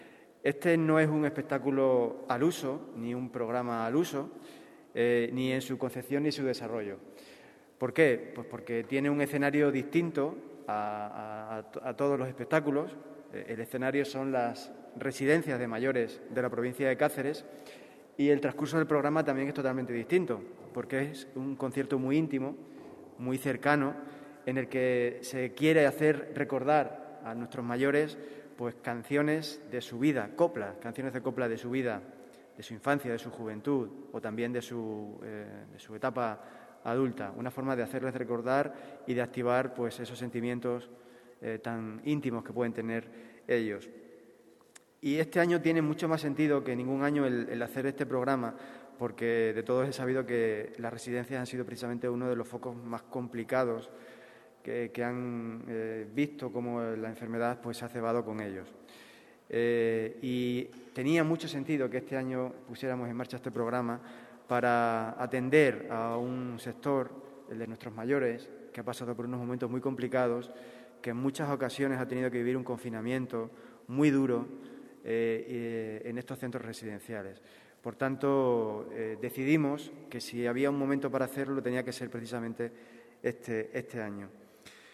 CORTES DE VOZ